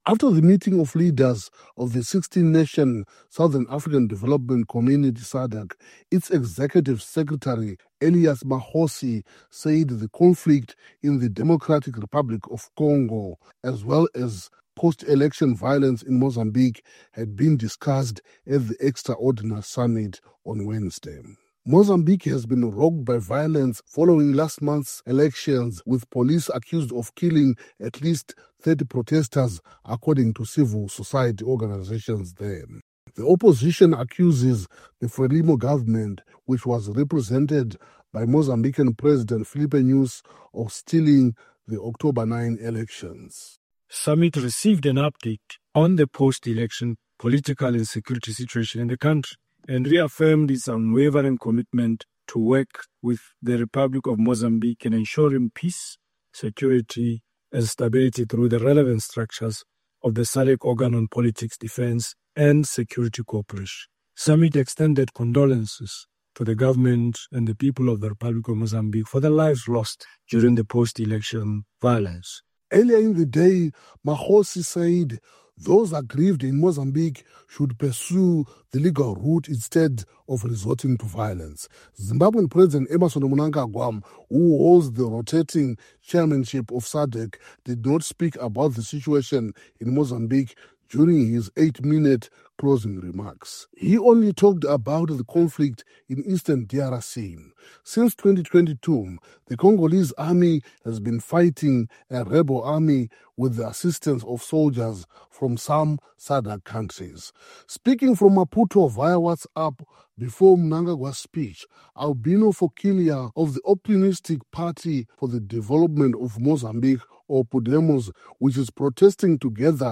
reports from Mount Hampden just outside the Zimbabwean capital, Harare, which is chairing the 16-nation group